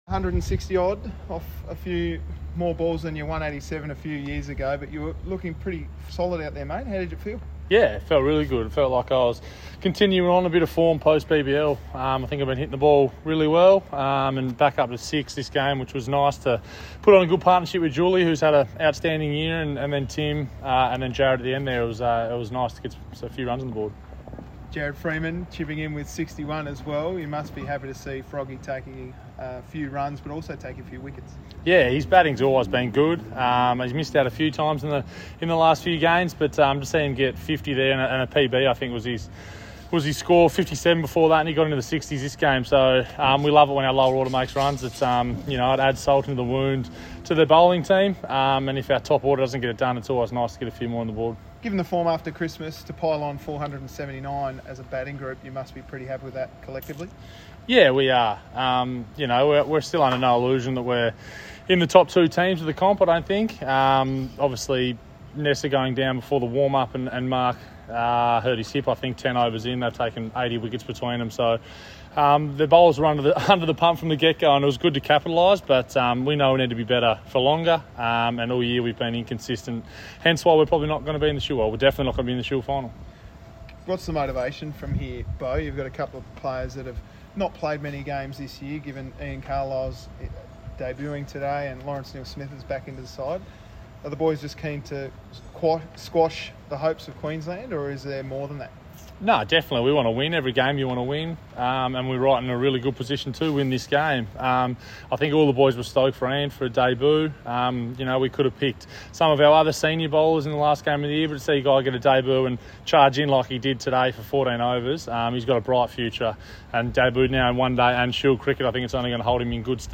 TAS all rounder Beau Webster (168* + 2-36) speaking following the conclusion of play on Day 2 at Blundstone Arena